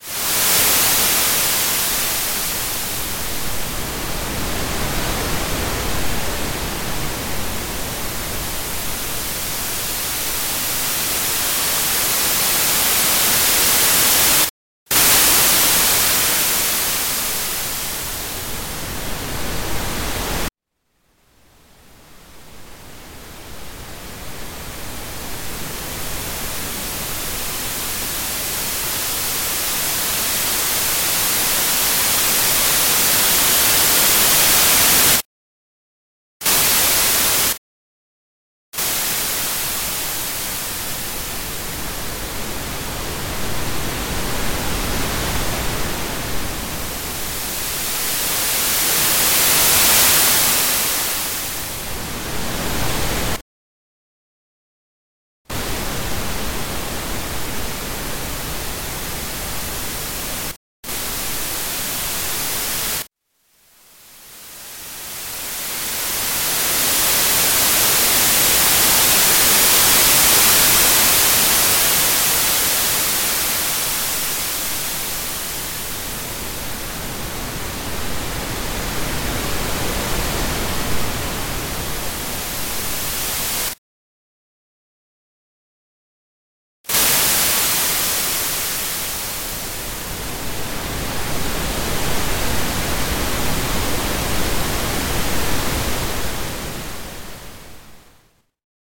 Sonic interpretation of Dot Drip Line Line - see
Created using Logic 9 in the composer's studio, 5 January 2017.